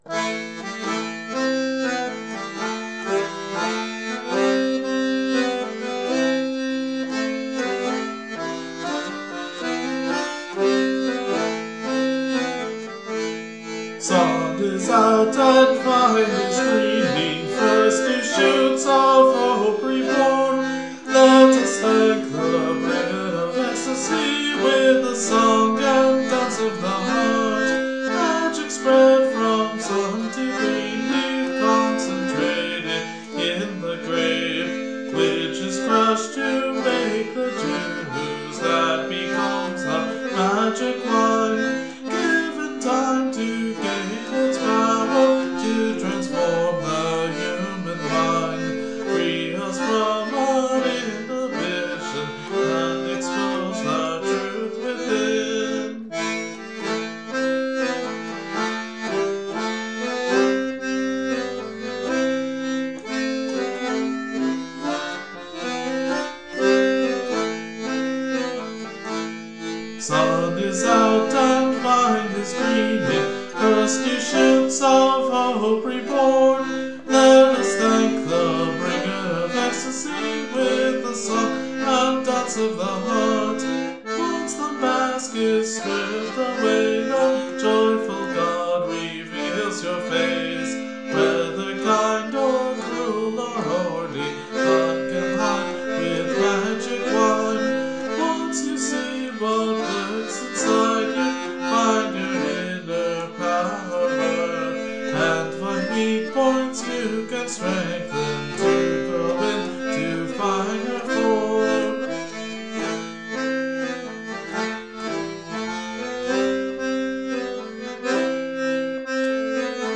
Rhythmically, this is in the kalamatianó rhythm of a "long" beat (3 eighths) followed by 2 "short" (2 eighths) beats. To add to the chaotic nature of the sound, I am attempting to use the accordion to imitate the effect of the aulos, double-reed instruments that were always played in a pair by the same musician using a circular breathing technique, although I suspect that these lines would sound far better using that instrument. And while this is nominally in Aeolean or minor mode, it definitely doesn't stick to that sense all that closely.